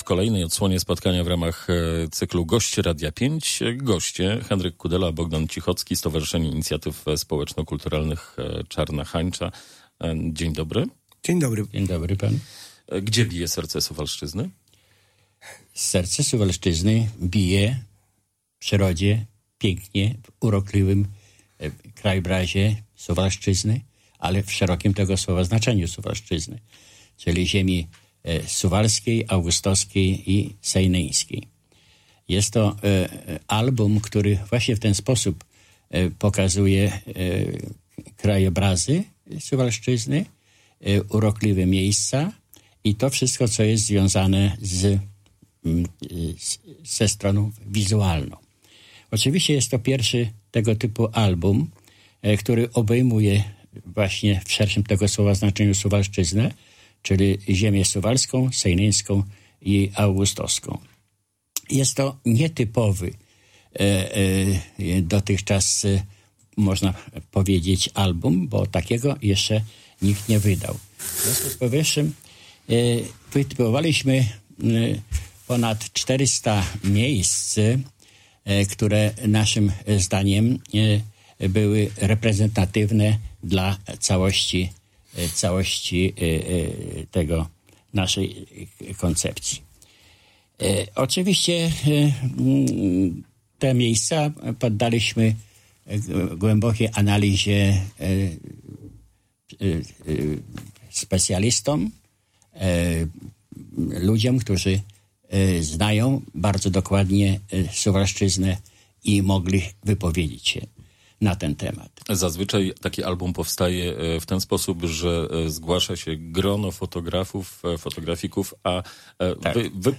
O publikacji mówili w audycji gościa Radia 5